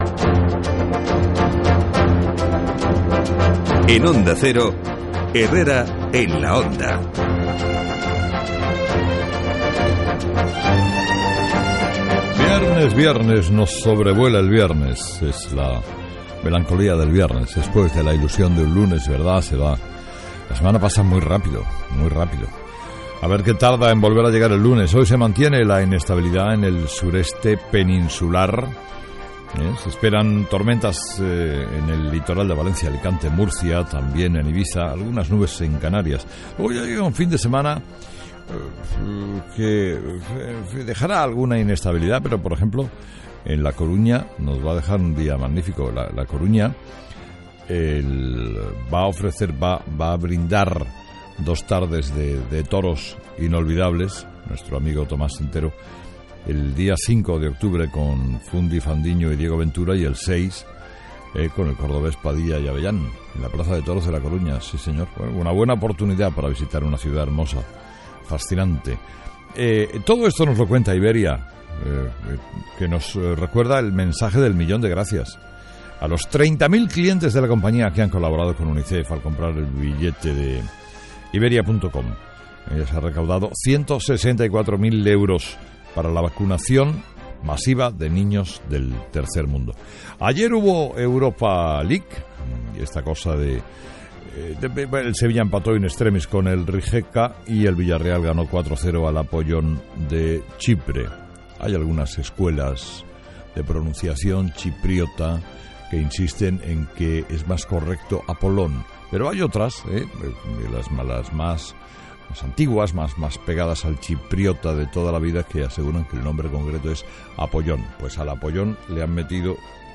Carlos Herrera comenta en su editorial cómo "se van contagiando los diparates" en las Comunidades Autónomas españolas, después de las últimas actuaciones del presidente de la Generalitat catalana, Artur Mas y del dirigente canario, Paulino Rivero.